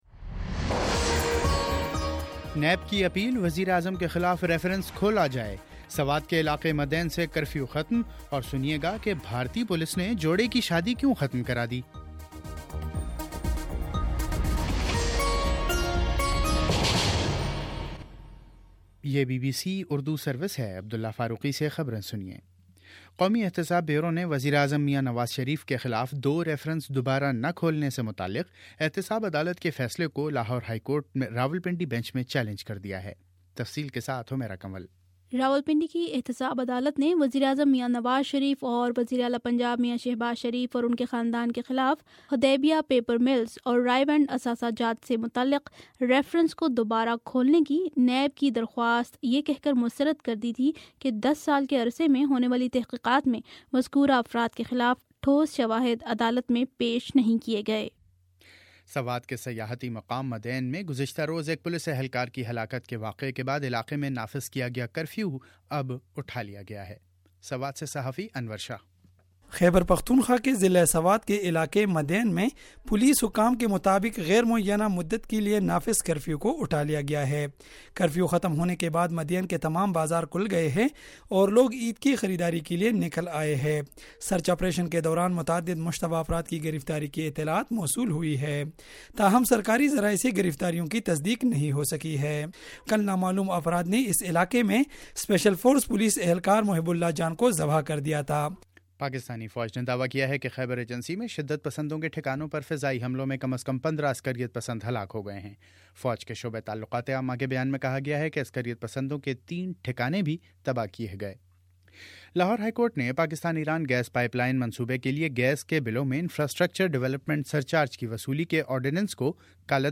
اکتوبر 03 : شام سات بجے کا نیوز بُلیٹن